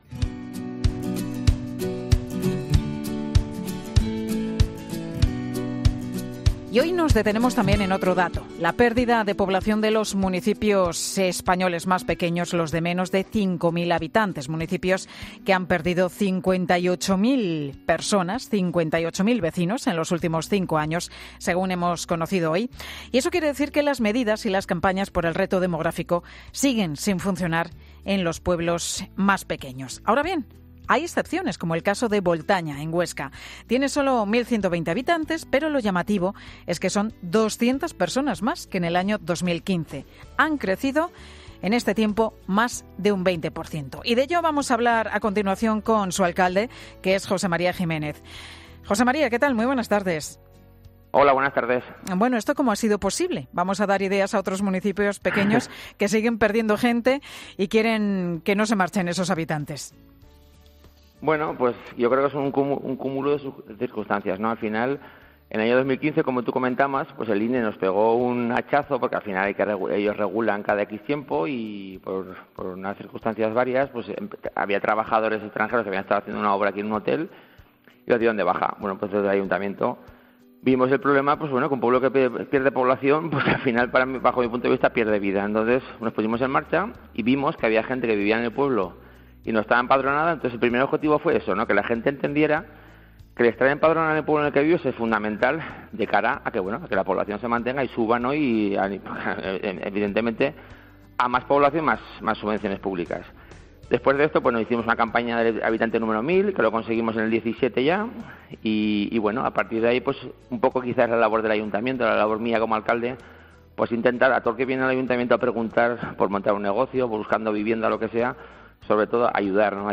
El alcalde de Boltaña, José María Jiménez, contó a la directora de ‘Mediodía COPE’, Pilar García-Muñiz, que el punto de inflexión fue el censo de 2015.